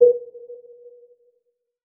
fnl/Assets/Extensions/Advanced_UI/User_Interface/Chill_Melodic/Club Radar.wav at main